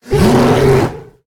语音
攻击
LOE_051_JungleMoonkin_Attack.ogg